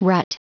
Prononciation du mot rut en anglais (fichier audio)